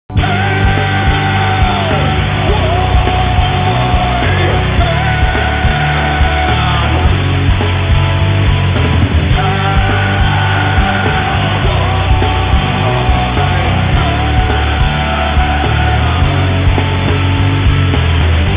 Zvonenia na mobil*(Ringtones):